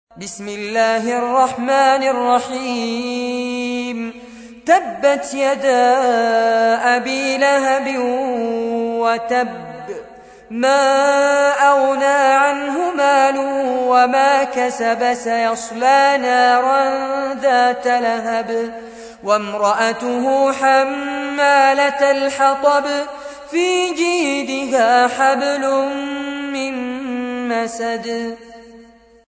تحميل : 111. سورة المسد / القارئ فارس عباد / القرآن الكريم / موقع يا حسين